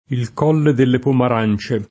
il k0lle delle pomar#n©e] (D’Annunzio)